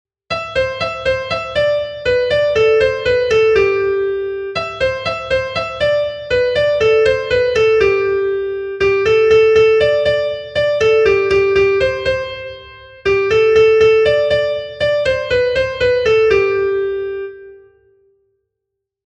Zortziko txikia (hg) / Lau puntuko txikia (ip)
A-A-B-B2